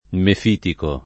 mefitico [ mef & tiko ]